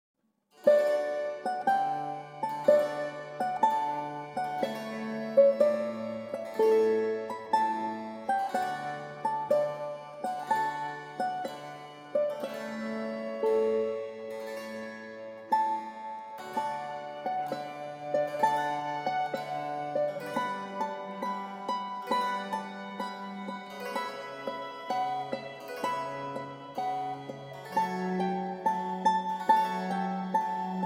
per due violini – liuto – basso continuo